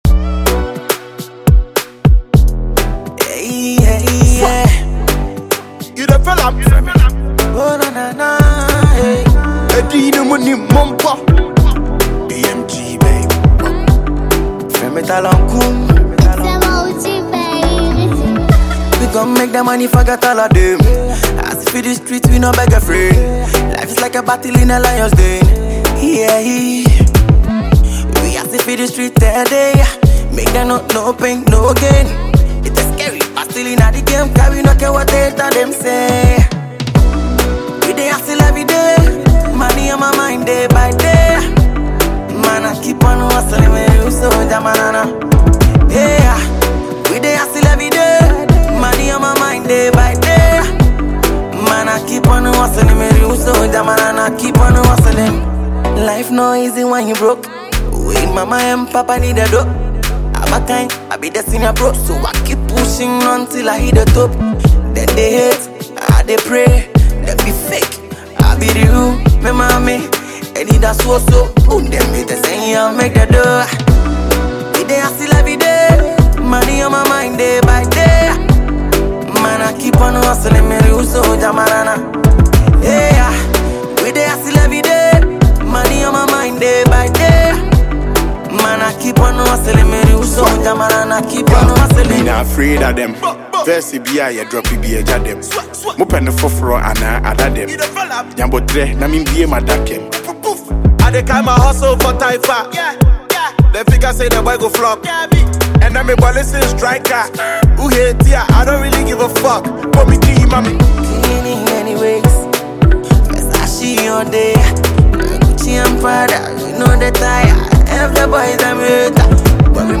Ghana MusicMusic
With his silky vocal tone